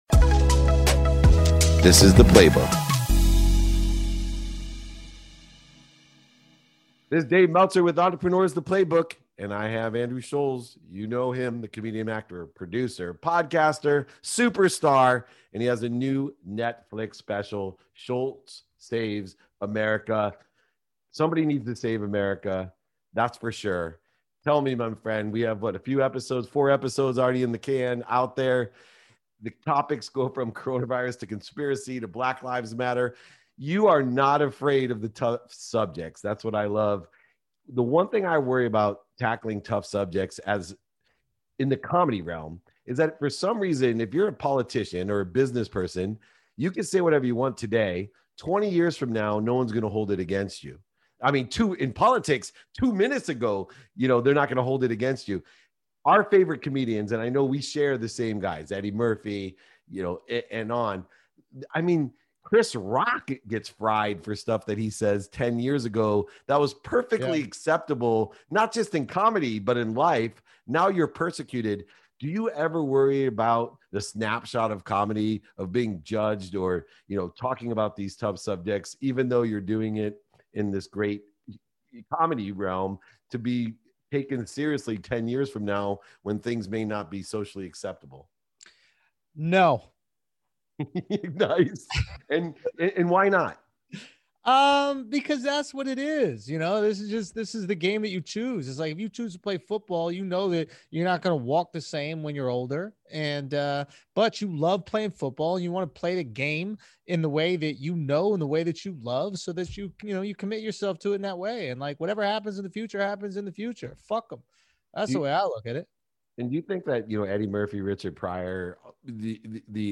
In this episode, David Meltzer has a conversation with comedian Andrew Schulz, who is known for his no-holds-barred style of comedy, and doesn't shy away from talking about the tricky subject of cancel culture.